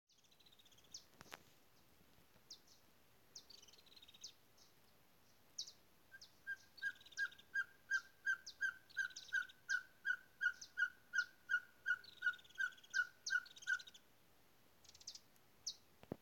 Caburé Chico (Glaucidium brasilianum)
Nombre en inglés: Ferruginous Pygmy Owl
Localidad o área protegida: San José de las Salinas
Condición: Silvestre
Certeza: Vocalización Grabada
CABURE-CHICO.mp3